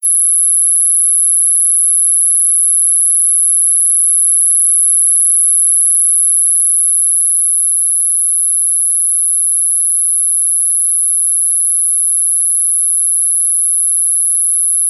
Recording of ultrasound standing wave peaks in the air.
Making ultrasound heard through pitching